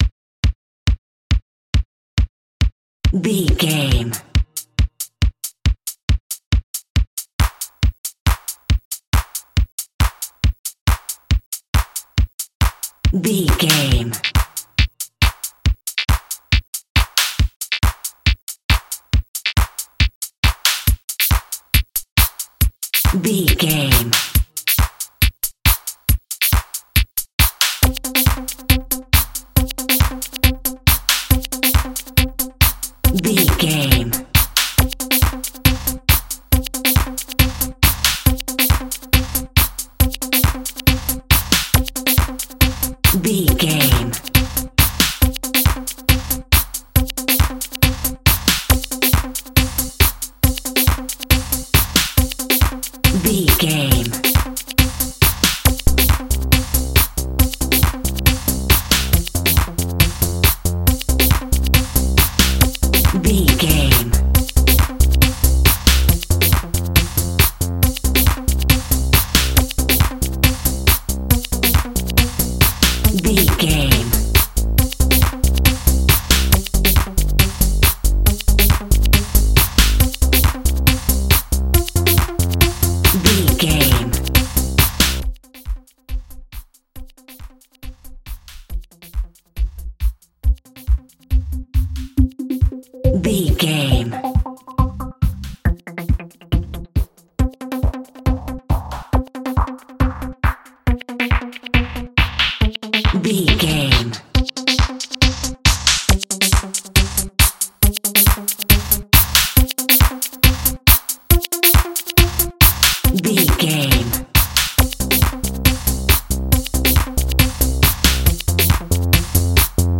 Cheesy Dance Music.
Aeolian/Minor
Fast
groovy
futuristic
drum machine
synthesiser
electro house
dance music